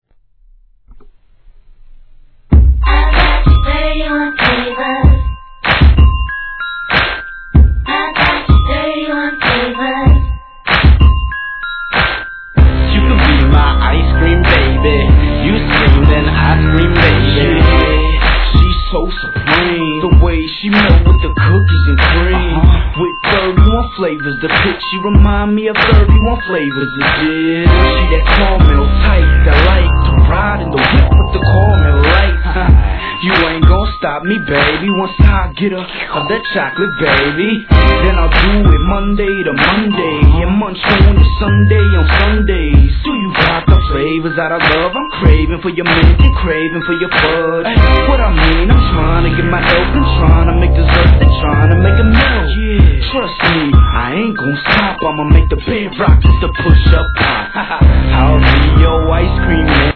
HIP HOP/R&B
オルゴールの様な鳴り物、随処に入るサンプリングがシンプルなビートに栄えるベイエリアサウンド！！